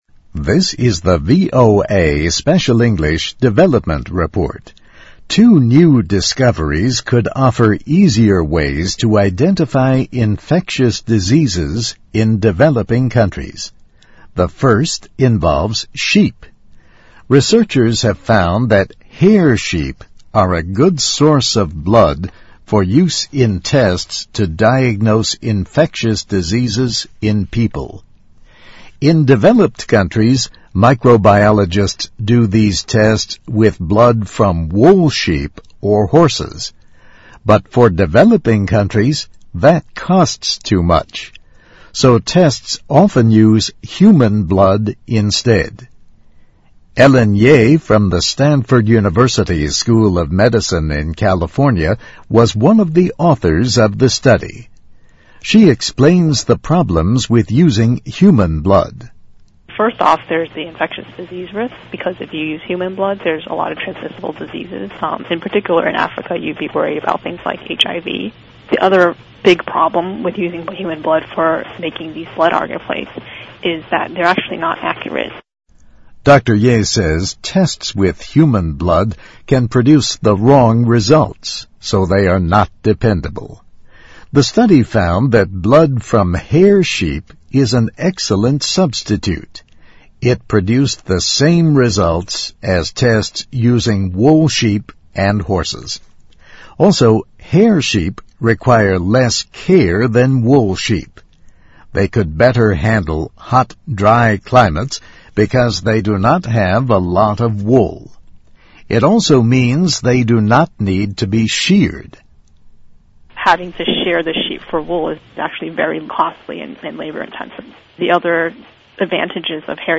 VOA慢速英语2009年-Development Report - New Ways for Poor Cou 听力文件下载—在线英语听力室